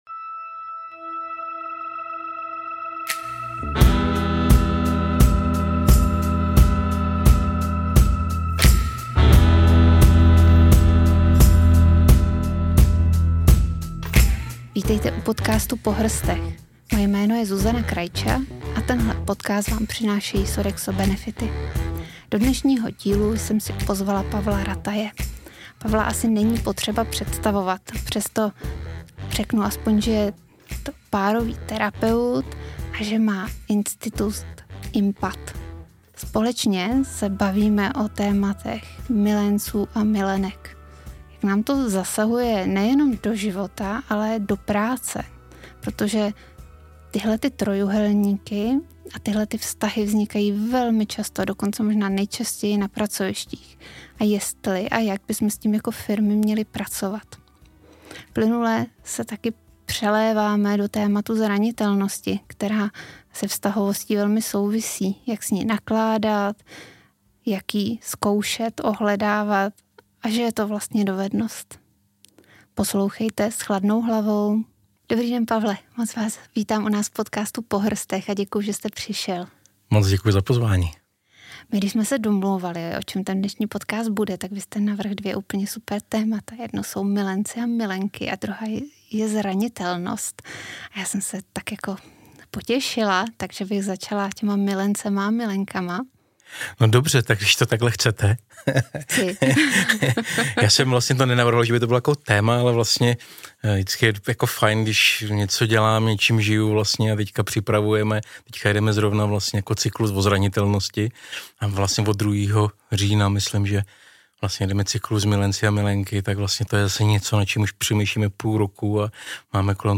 S tímto tématem je úzce spojena i zranitelnost, kterou probíráme v druhé polovině našeho rozhovoru.